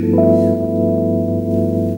OLD EP ROLL.wav